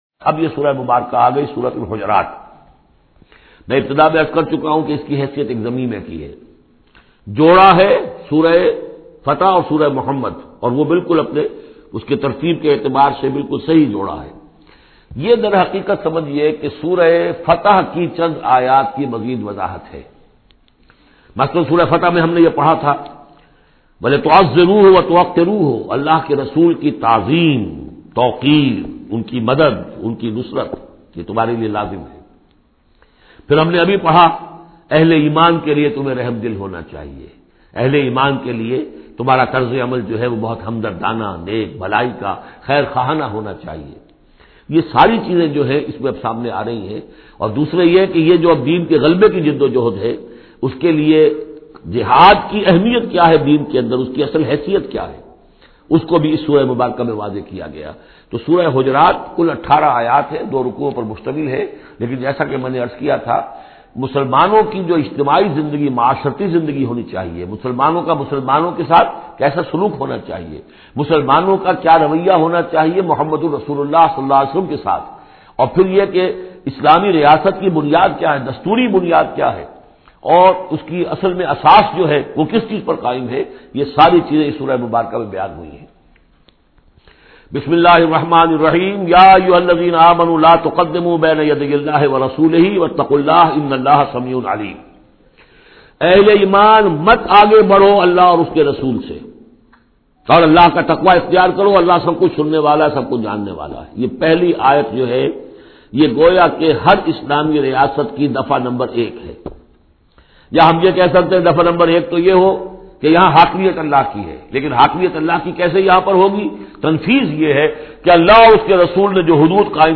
Surah Hujurat Tafseer by Dr Israr Ahmed
Surah Hujurat is 49th chapter of holy Quran. Listen online mp3 tafseer of Surah Hujurat in the voice of Dr Israr Ahmed.